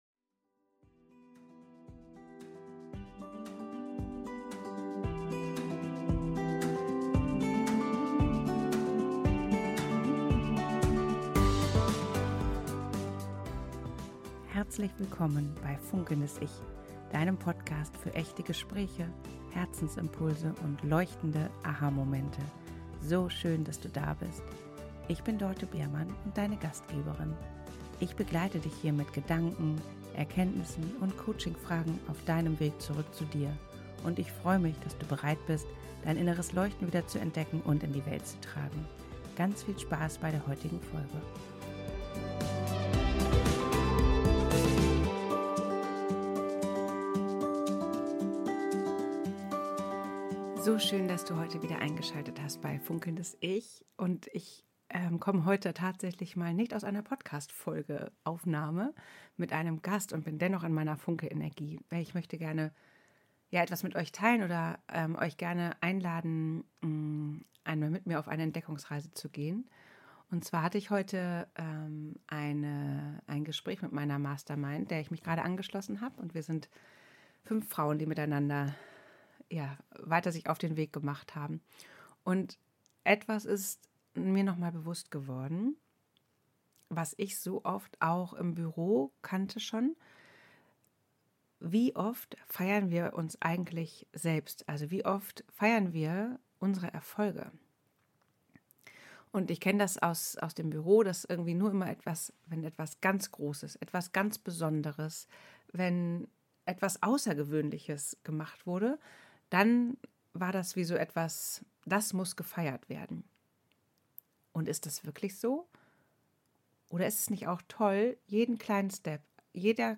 Wenn du dich zu selten feierst: diese Folge erinnert dich daran, wie wertvoll du auf deinem Weg bist. In dieser Solo-Folge nehme ich dich ganz nah mit in meine eigene Entwicklung der letzten Tage.